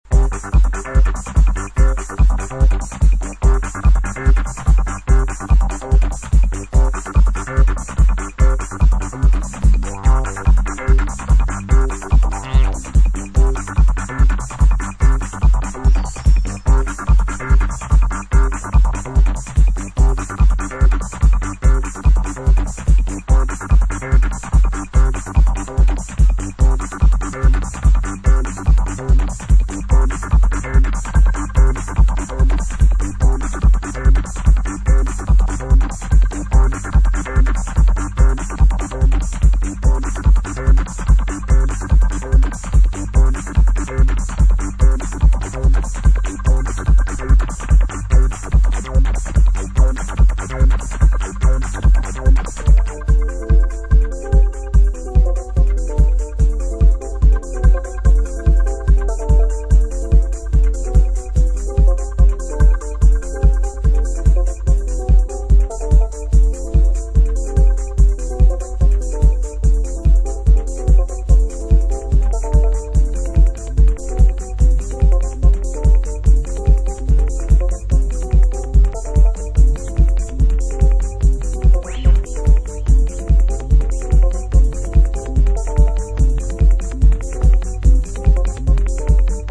style strings over long and wide arrangements.
Techno Detroit